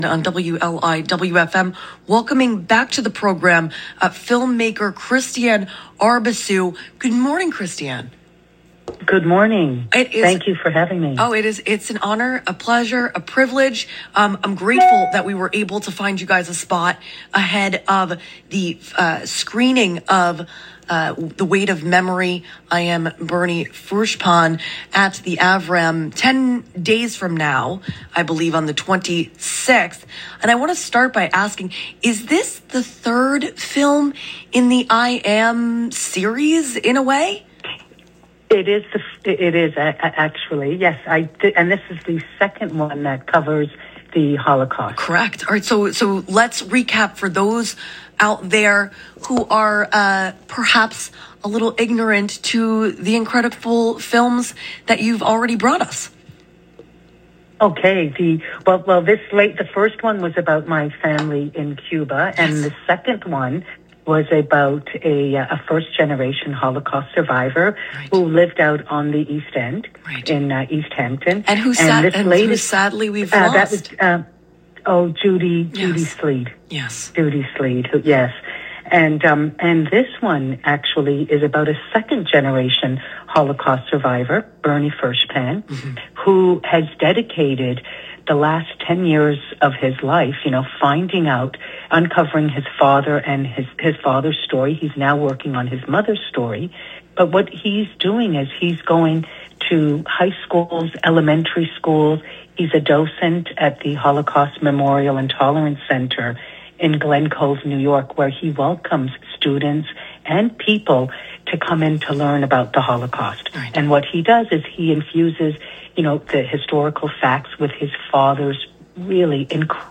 Download NPR INTERVIEW JUNE 16 2025